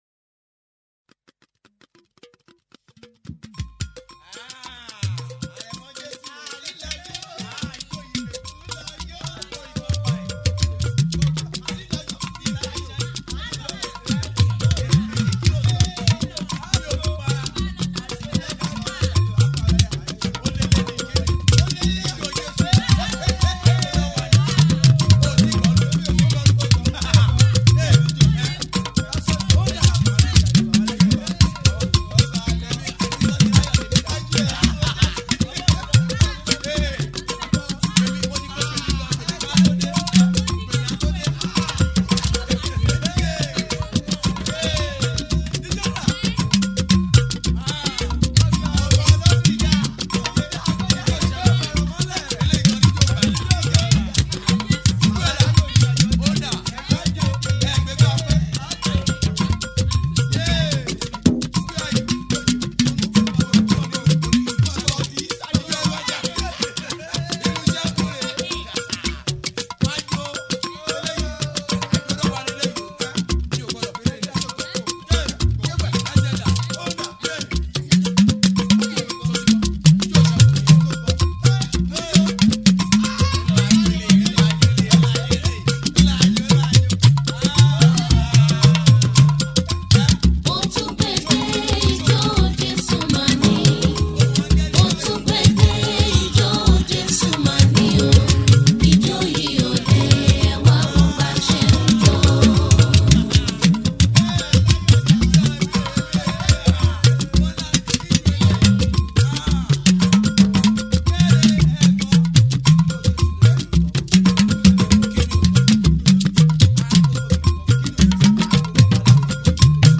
Fuji Gospel